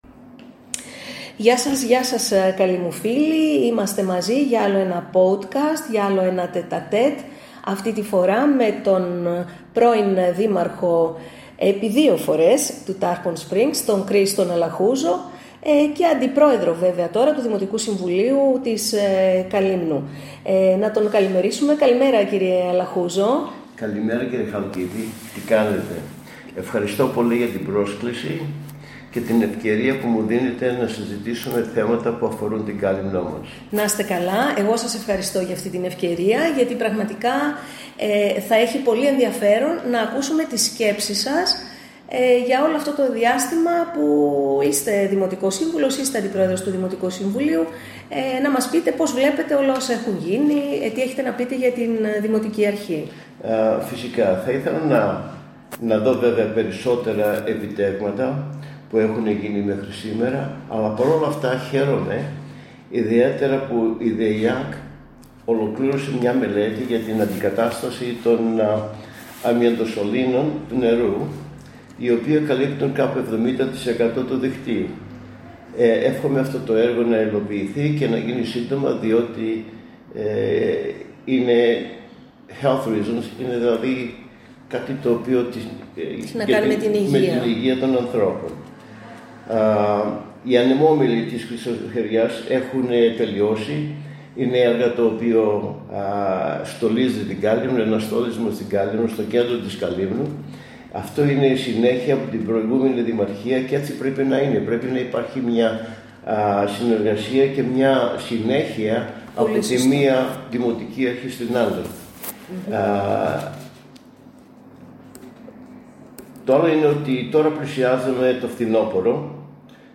Σε ένα ιδιαίτερα ενδιαφέρον Τετ-α-τετ, ο επί δύο θητείες δήμαρχος του Τάρπον Σπρινγκς και αντιπρόεδρος του Δημοτικού Συμβουλίου Καλύμνου, Κρις Αλαχούζος, μίλησε με ειλικρίνεια για τα ζητήματα που απασχολούν την Κάλυμνο, καταθέτοντας ταυτόχρονα προτάσεις και σκέψεις για το μέλλον του τόπου. Ο κ. Αλαχούζος ξεκίνησε εκφράζοντας την ικανοποίησή του για την ολοκλήρωση της μελέτης αντικατάστασης των αμιαντοσωλήνων στο δίκτυο ύδρευσης, ένα έργο που χαρακτήρισε αναγκαίο για τη δημόσια υγεία.